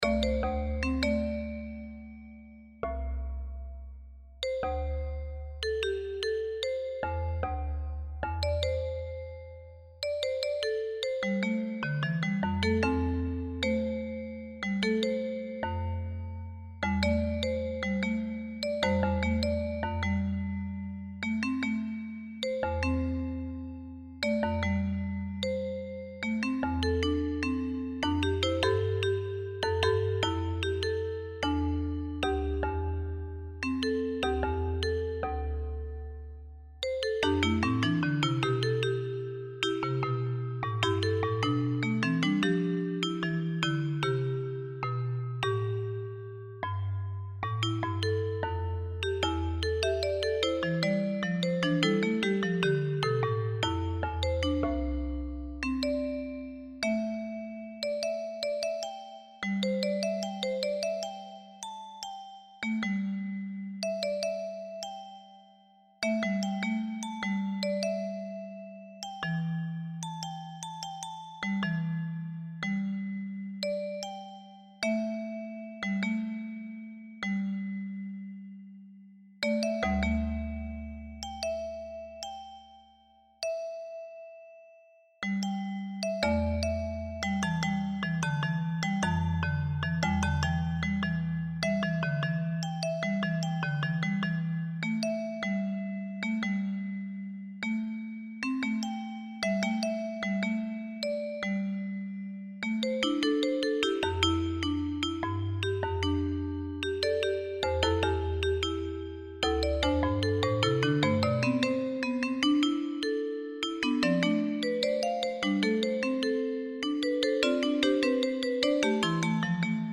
Sonification of Streamflow Data
Visual of Sample Song Make music from streamflow and water quality data.